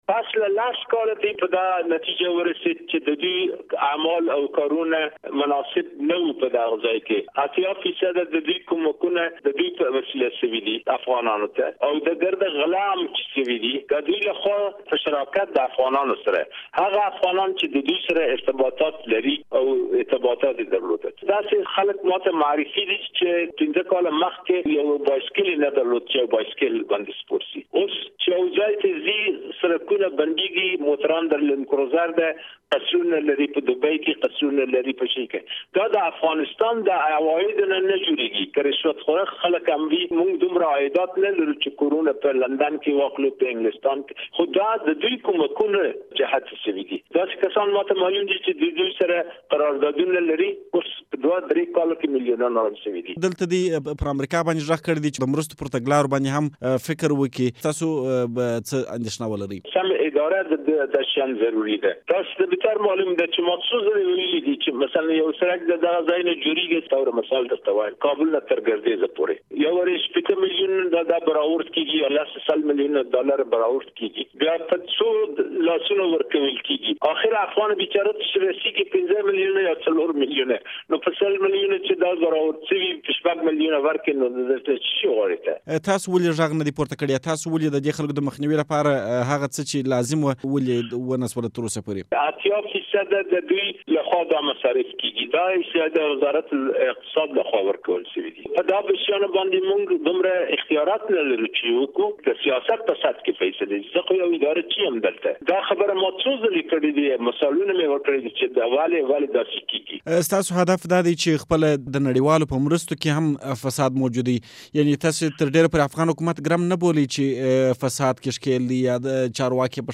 له عزيز الله لودين سره مرکه